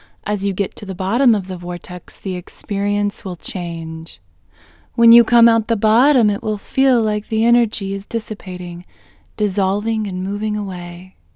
IN – the Second Way – English Female 16